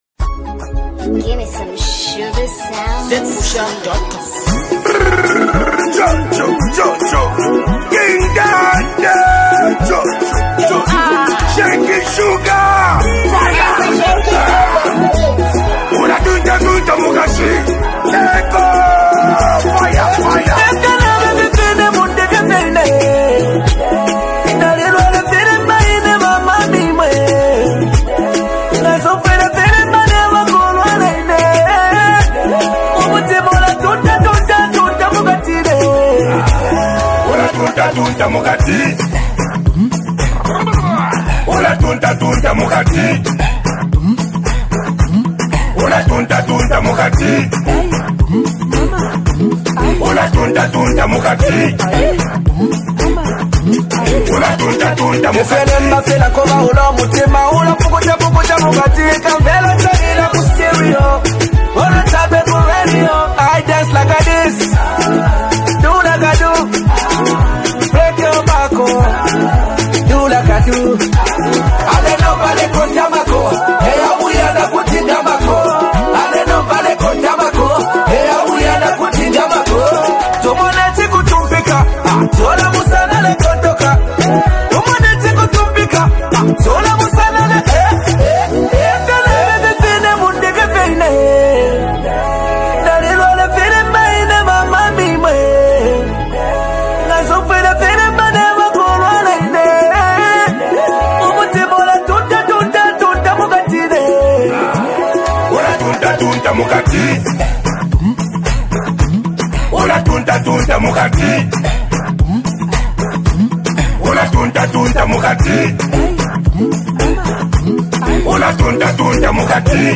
dancehall
the jam is clearly meant for the big speakers.